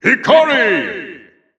The announcer saying Mythra's name in Japanese and Chinese releases of Super Smash Bros. Ultimate.
Mythra_Japanese_Announcer_SSBU.wav